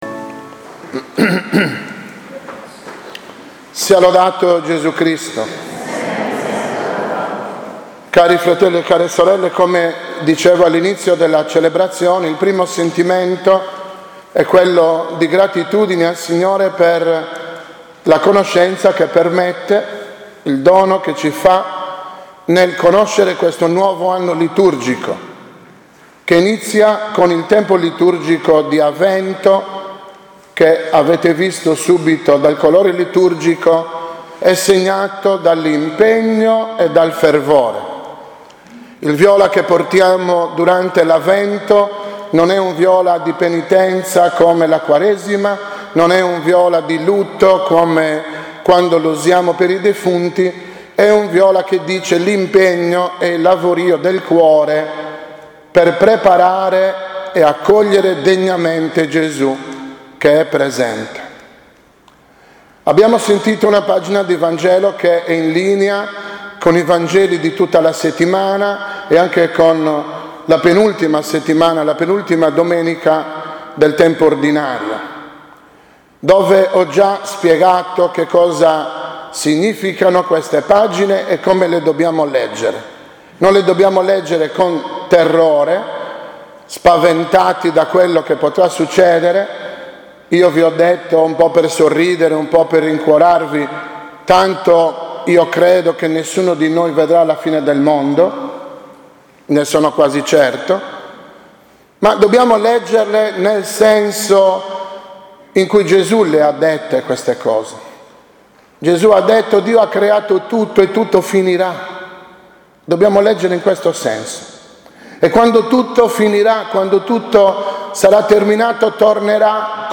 Parrocchia Santi Pietro e Paolo – Cagliari » L'Amore di Dio regni sempre nei vostri cuori » Omelie